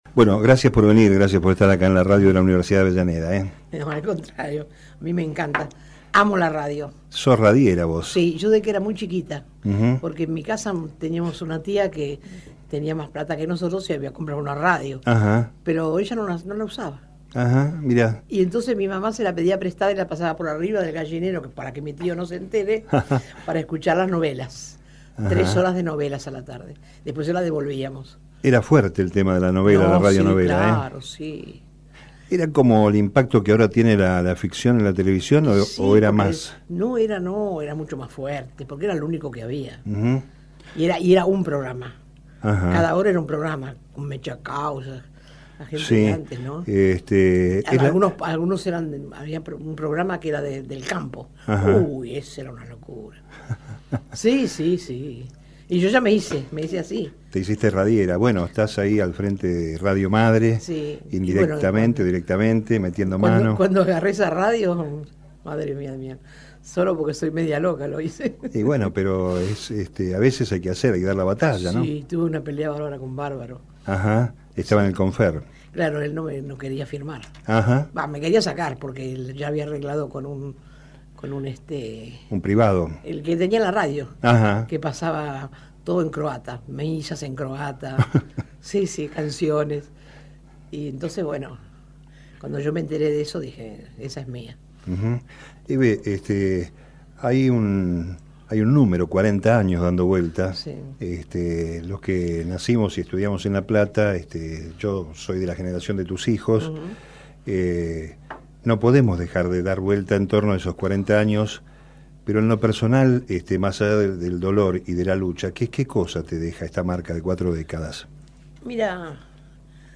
Entrevista realizada en 2016 a Hebe de Bonafini presidenta de la Asociación Madres de Plaza de Mayo, organización de madres de detenidos desaparecidos durante la última dictadura militar. Las Madres son el principal símbolo de resistencia a la última dictadura cívico-militar, uno de los primeros movimientos de denuncia del terrorismo de Estado, y referentes en Argentina y en el mundo de la lucha por los derechos humanos.